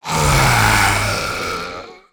burer_die_1.ogg